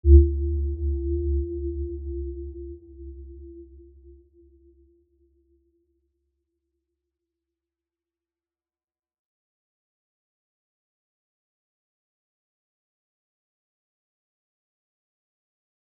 Aurora-E2-mf.wav